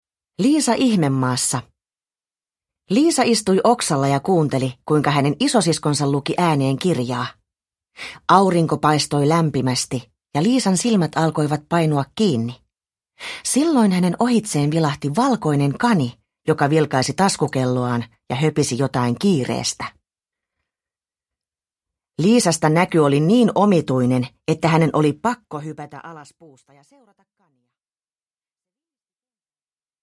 Liisa Ihmemaassa – Ljudbok – Laddas ner